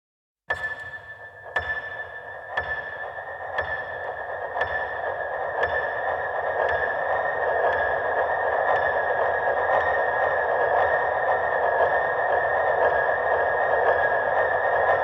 Perkusja
Gitary
Instrumenty klawiszowe, bass, instr. perkusyjne, piano